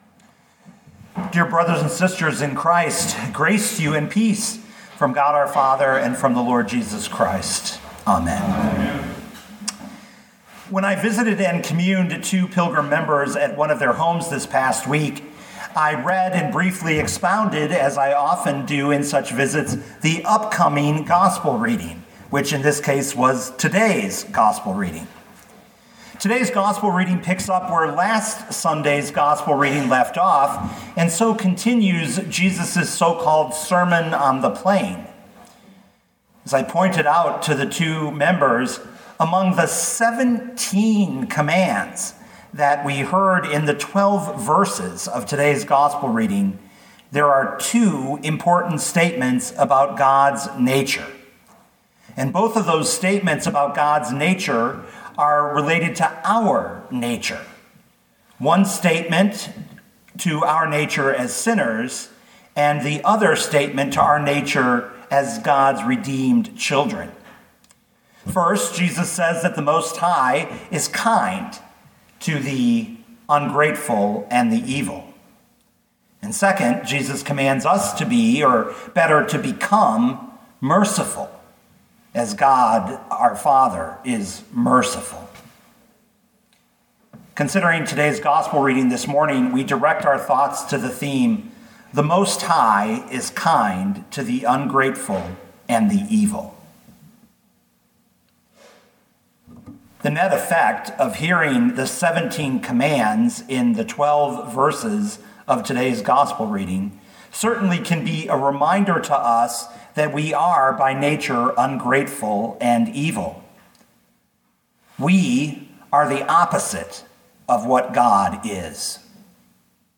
Sermons
The Seventh Sunday after the Epiphany, February 20, 2022